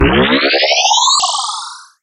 04970 analog game charge ding
analog charge ding energy game sci-fi simple sound effect free sound royalty free Sound Effects